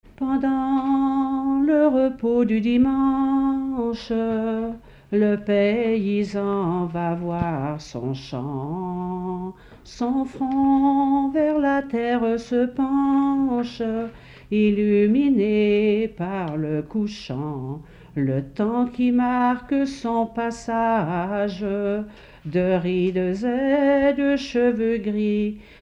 Localisation Saint-Julien-en-Genevois
Catégorie Pièce musicale inédite